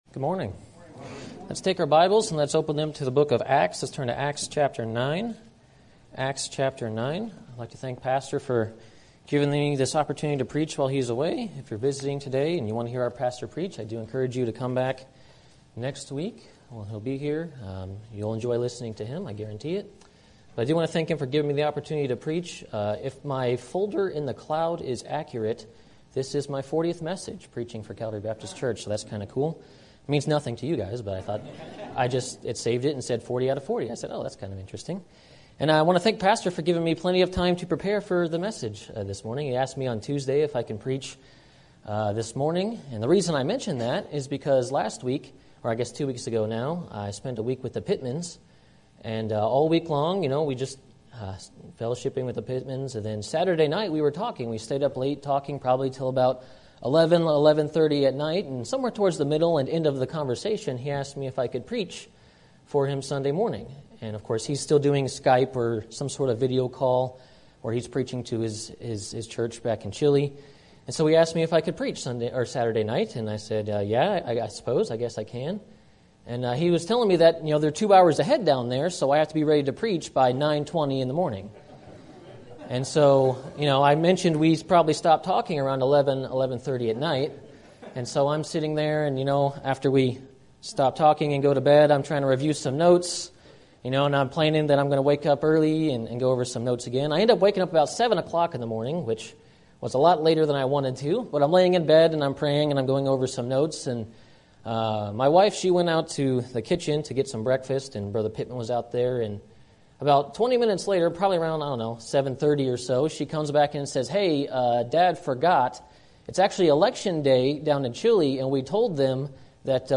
Sermon Topic: General Sermon Type: Service Sermon Audio: Sermon download: Download (21.64 MB) Sermon Tags: Acts Salvation Paul Repent